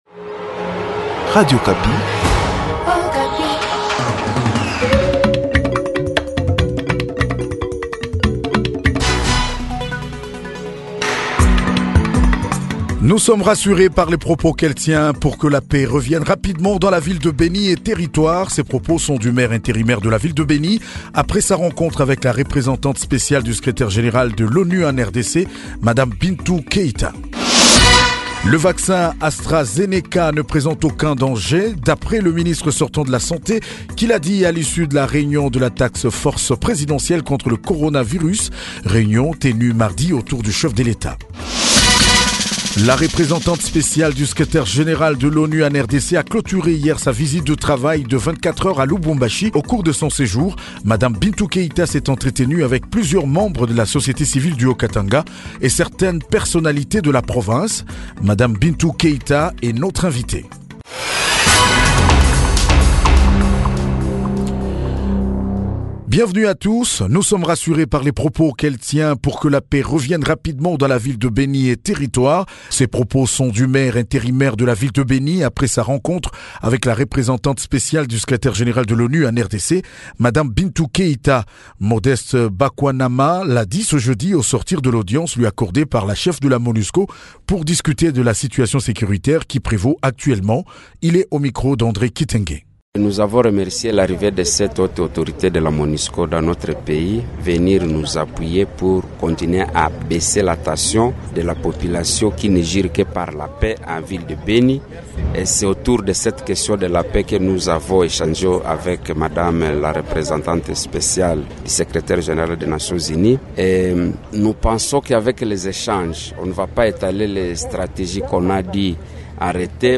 JOURNAL SOIR DU 15 AVRIL 2021